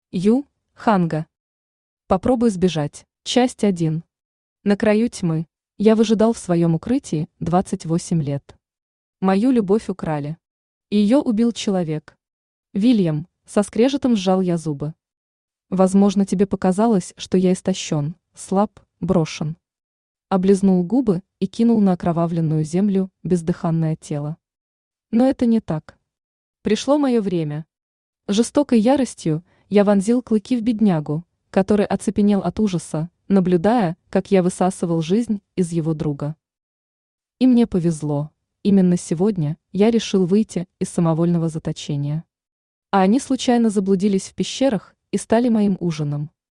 Аудиокнига Попробуй сбежать | Библиотека аудиокниг
Aудиокнига Попробуй сбежать Автор Ю.Ханга Читает аудиокнигу Авточтец ЛитРес.